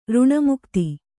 ♪ řṇamukti